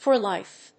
アクセントfor lífe